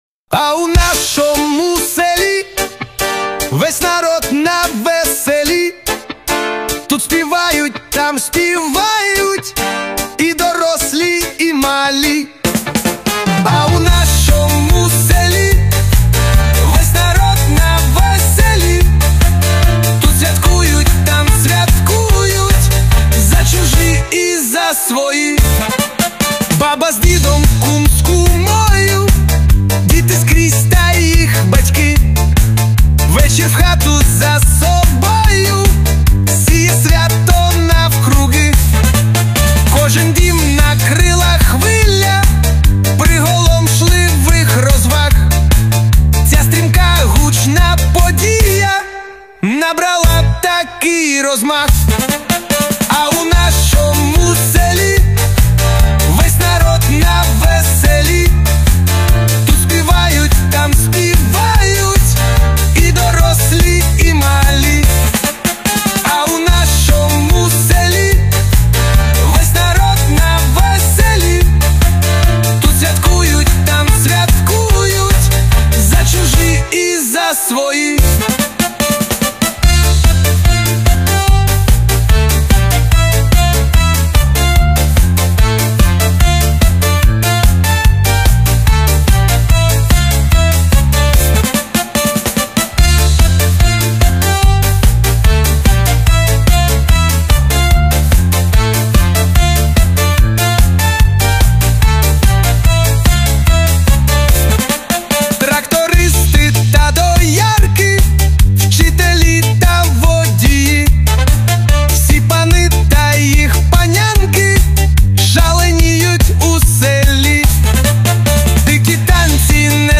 • Жанр:Рок